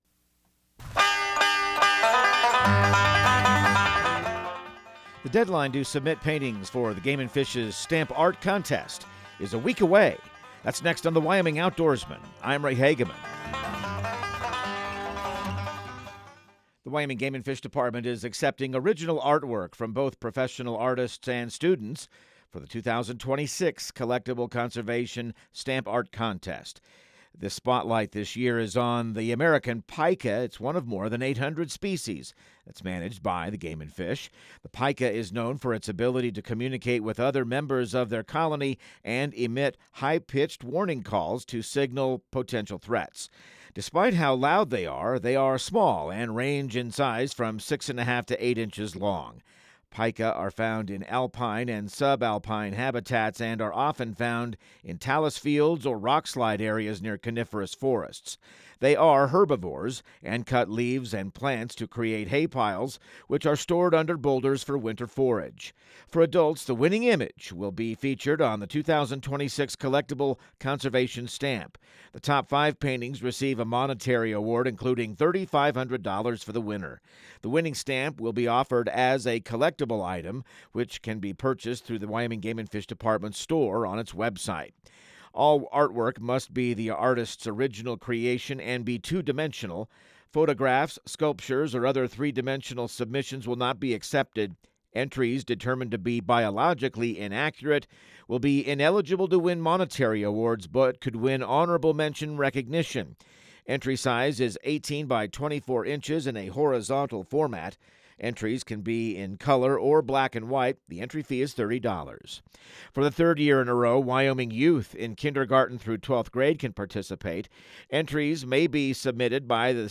Radio news | Week of February 17